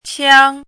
chinese-voice - 汉字语音库
qiang1.mp3